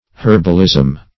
Herbalism \Herb"al*ism\, n. The knowledge of herbs.